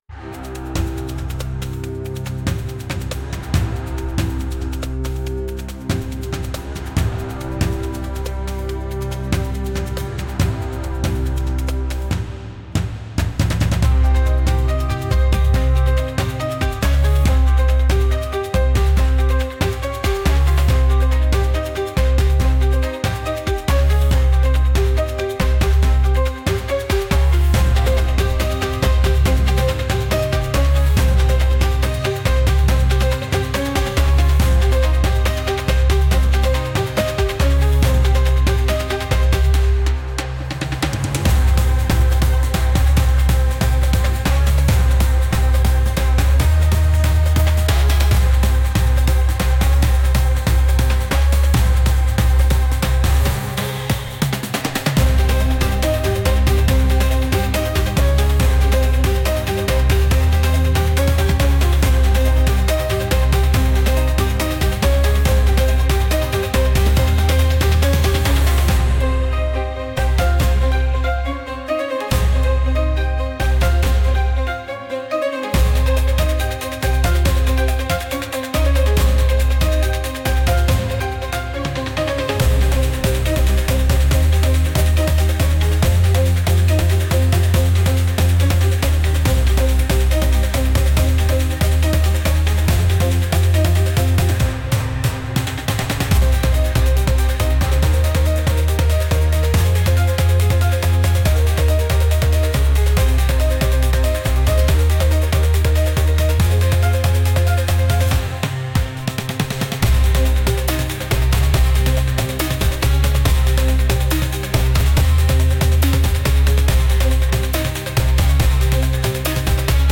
Instrumental / 歌なし
🌿🥁🦅 Folk music, tribal dance
独特な民族楽器の音色と、絶え間なく続くトライバルなビートが特徴の一曲。
この曲の持ち味は、熱くなりすぎない「クールなかっこよさ」です。